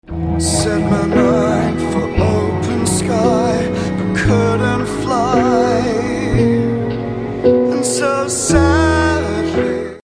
MOVIE Opening Theme Song